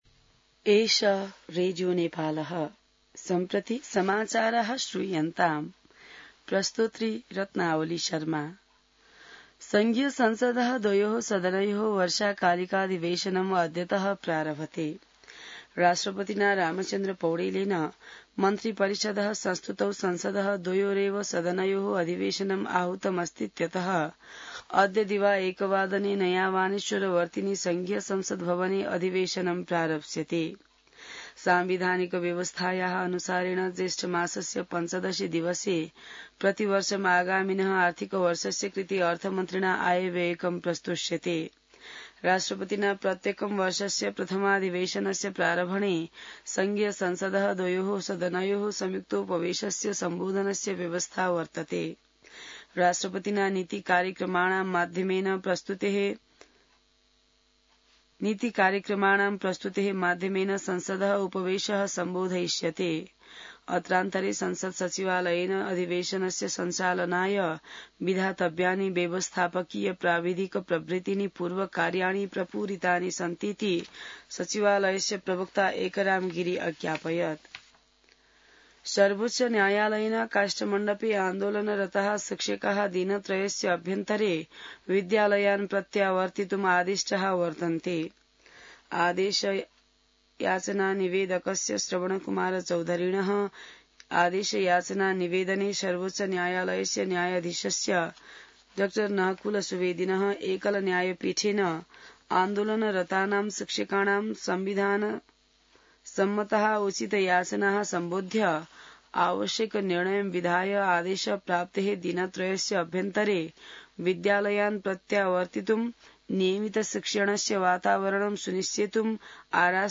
An online outlet of Nepal's national radio broadcaster
संस्कृत समाचार : १२ वैशाख , २०८२